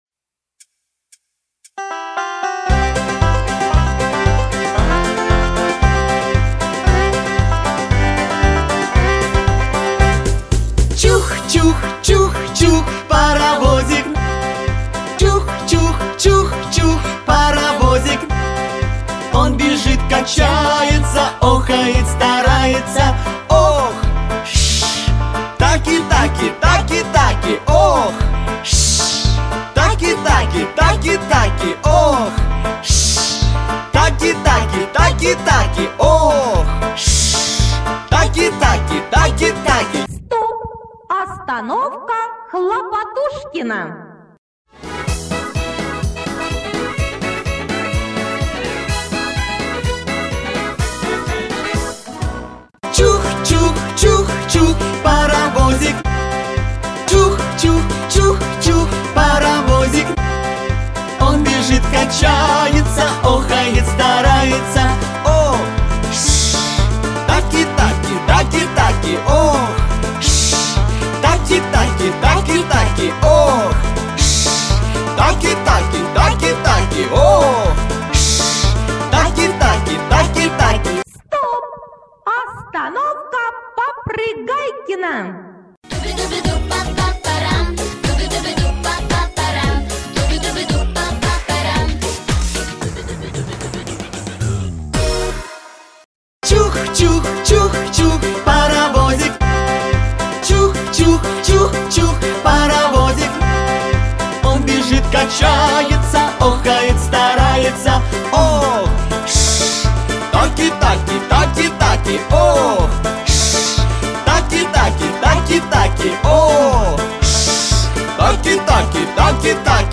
Игра- анимашка для детей с движениями - Паровозик чух-чух таки-таки